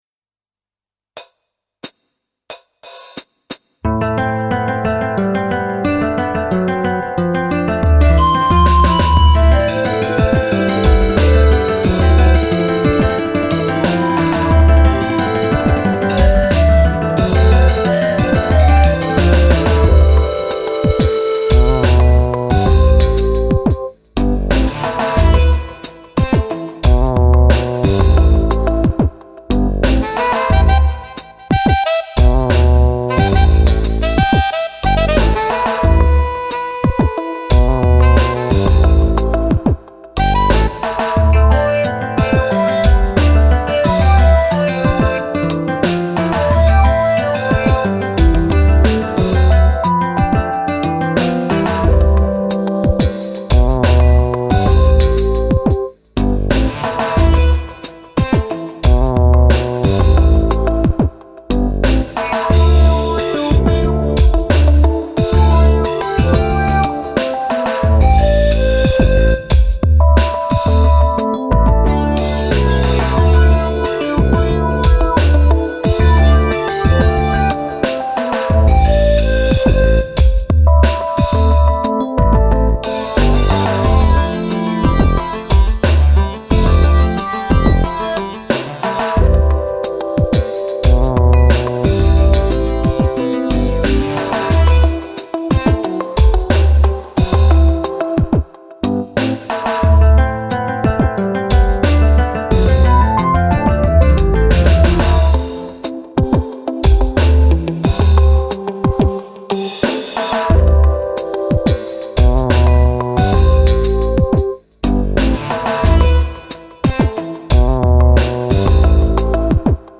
音源は、ＳＣ８８２０を購入したので、さらに良くなっているはず。